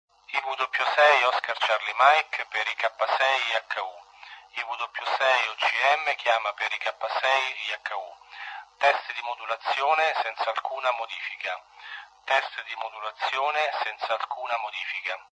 Ci siamo subito accorti che la modulazione di questi apparati esalta molto i toni acuti rendendo l'ascolto poco gradevole.